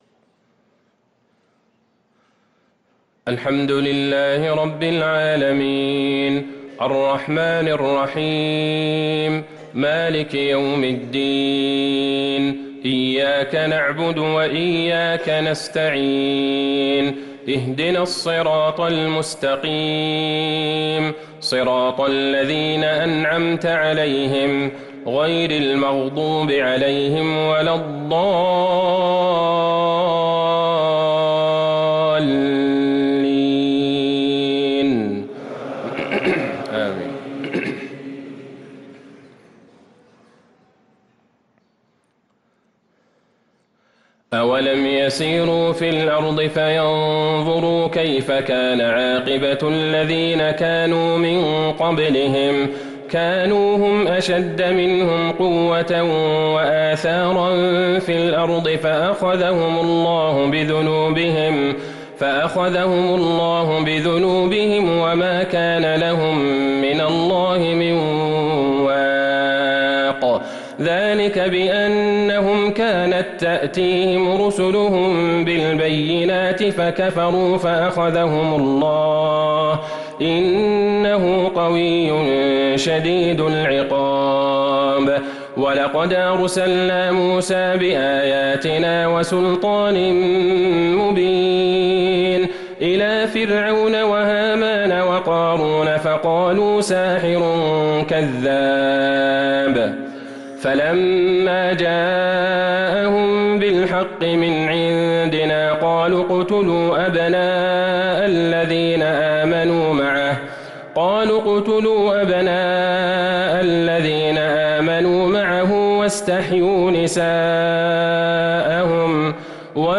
صلاة العشاء للقارئ عبدالله البعيجان 2 جمادي الآخر 1444 هـ
تِلَاوَات الْحَرَمَيْن .